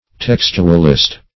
Textualist \Tex"tu*al*ist\, n. A textman; a textuary.